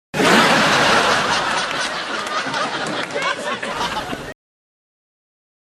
Laughs 4